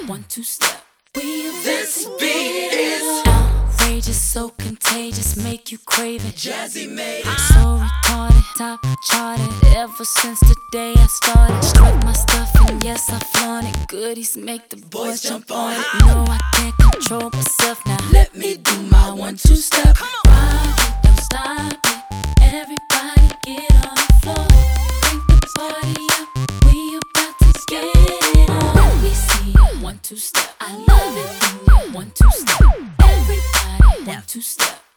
Жанр: Иностранный рэп и хип-хоп / R&b / Соул / Рэп и хип-хоп